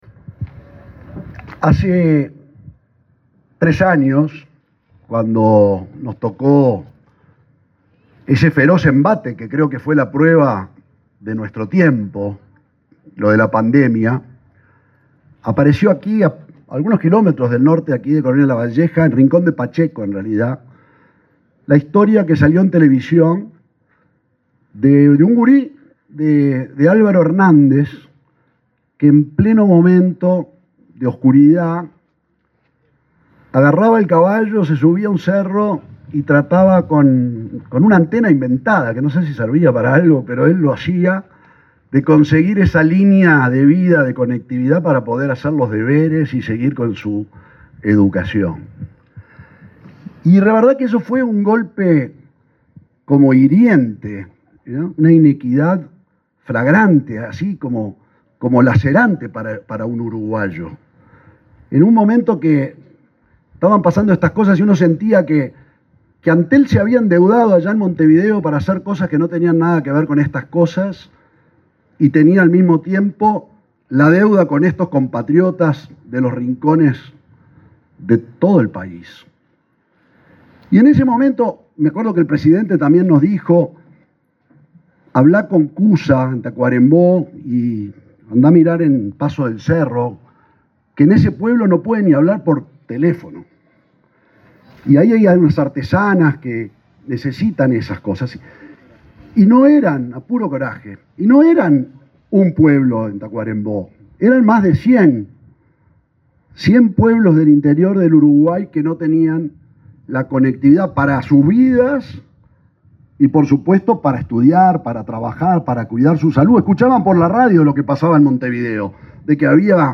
Palabras del presidente de Antel, Gabriel Gurméndez
Palabras del presidente de Antel, Gabriel Gurméndez 04/10/2023 Compartir Facebook X Copiar enlace WhatsApp LinkedIn Con la presencia del presidente de la República, Luis Lacalle Pou, se realizó, este 4 de octubre, el acto de celebración por el 100% de conectividad a internet en todos los centros educativos públicos del país mediante el sistema de banda ancha. En el evento el presidente de Antel, Gabriel Gurméndez, realizó declaraciones.